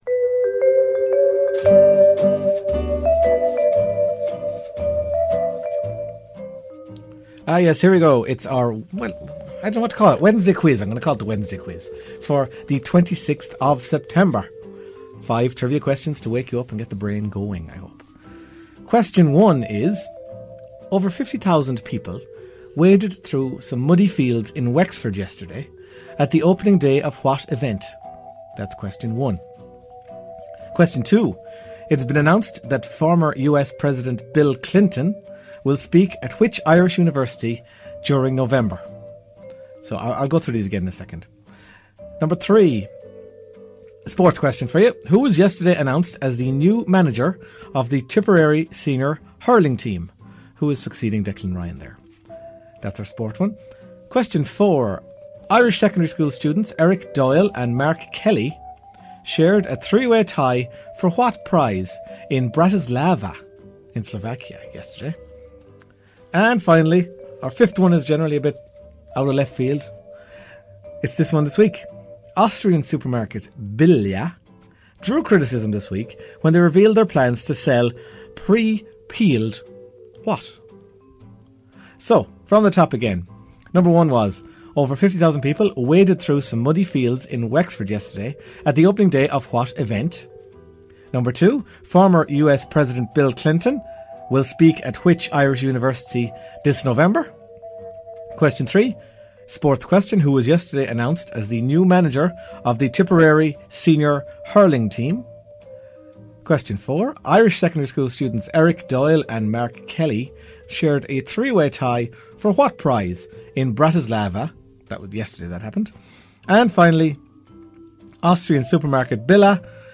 A rare Wednesday appearance for my trivia quiz. The show was broadcast on September 26, 2012.